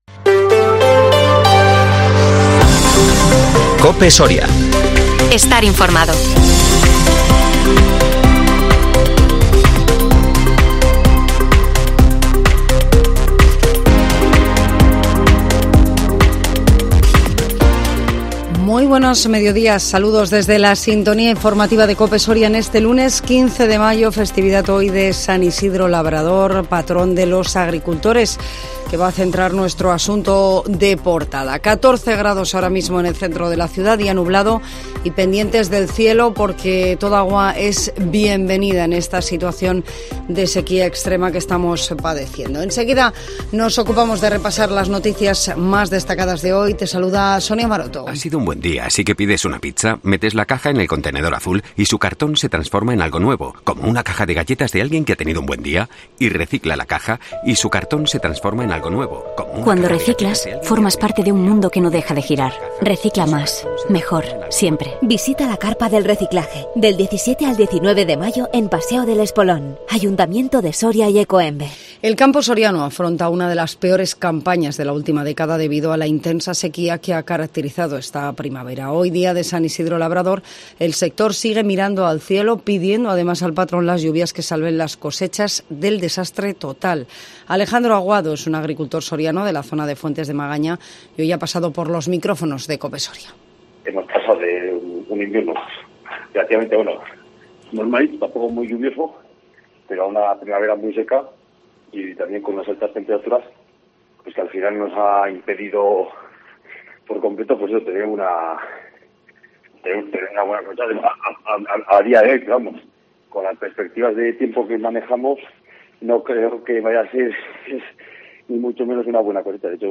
INFORMATIVO MEDIODÍA COPE SORIA 15 MAYO 2023